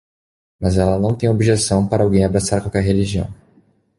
Pronunciado como (IPA)
/o.bi.ʒeˈsɐ̃w̃/